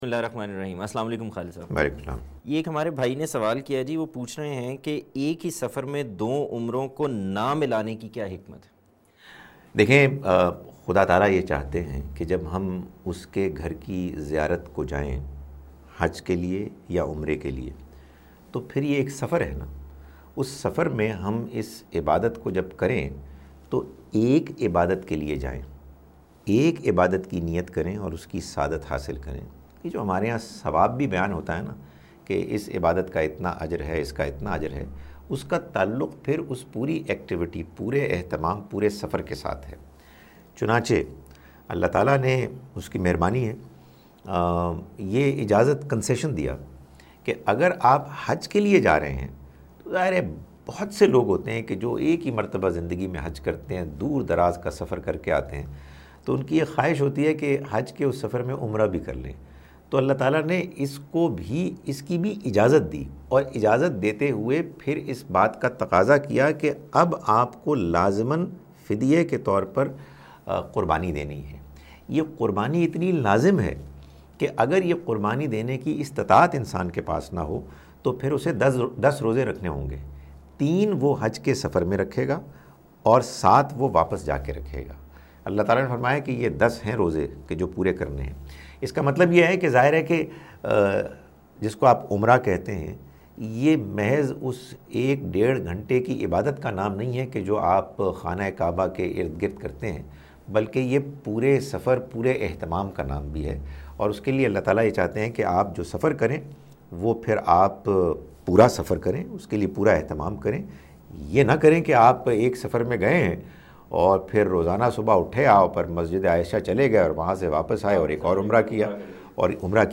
Program "Ask A Question" where people ask questions and different scholars answer their questions